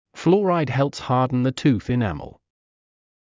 ﾌﾛｰﾗｲﾄﾞ ﾍﾙﾌﾟｽ ﾊｰﾃﾞﾝ ｻﾞ ﾄｩｰｽ ｲﾅﾓｳ